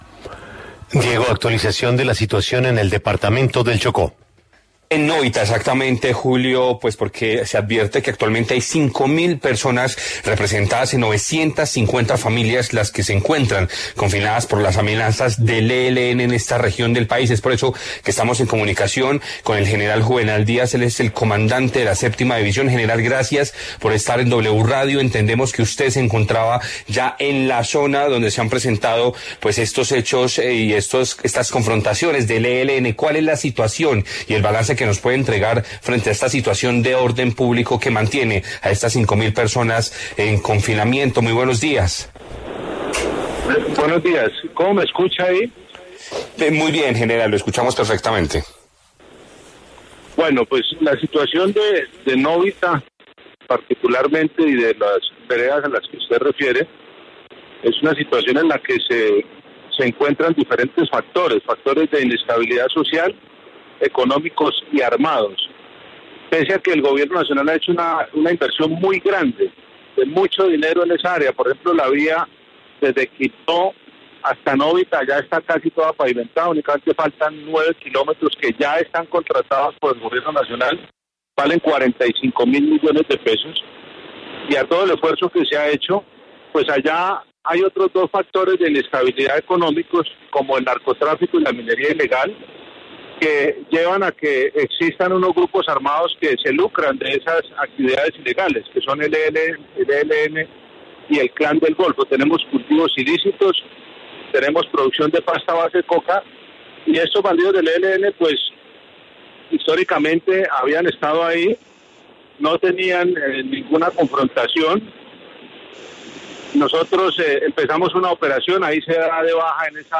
En La W, el general Juvenal Díaz hizo un balance sobre la situación de orden público que se vive en Nóvita por cuenta de la presencia de grupos armados.